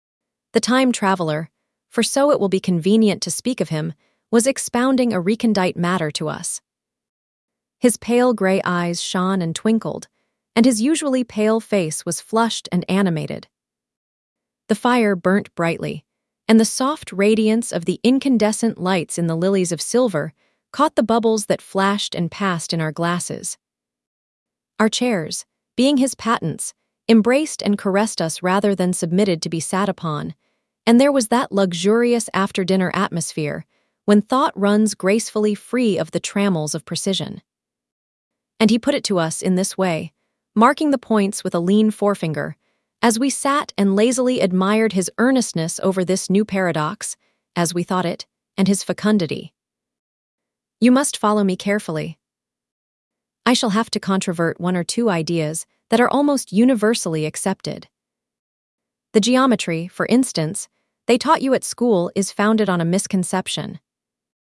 Kokoro-FastAPI - Dockerized FastAPI wrapper for Kokoro-82M text-to-speech model w/CPU ONNX and NVIDIA GPU PyTorch support, handling, and auto-stitching